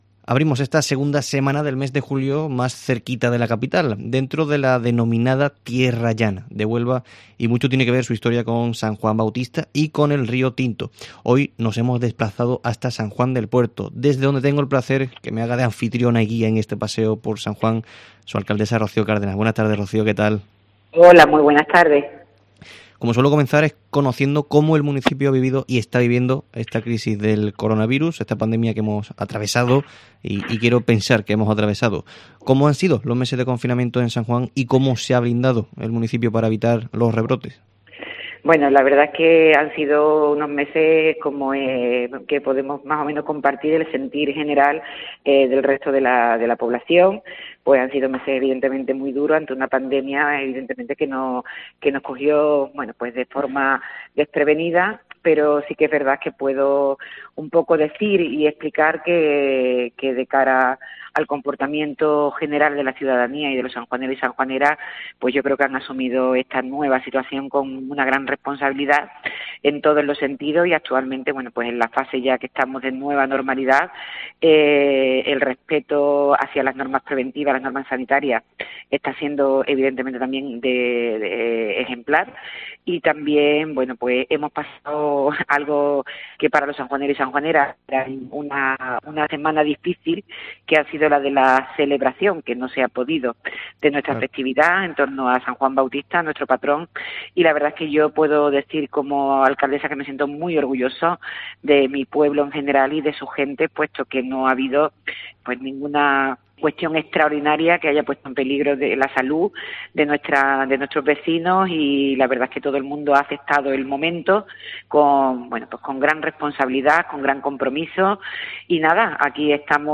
Rocío Cárdenas, alcaldesa de San Juan del Puerto